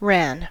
Ääntäminen
Etsitylle sanalle löytyi useampi kirjoitusasu: ran RAN Ran Ääntäminen : IPA : /ɹæn/ US : IPA : [ɹæn] Haettu sana löytyi näillä lähdekielillä: englanti Käännöksiä ei löytynyt valitulle kohdekielelle.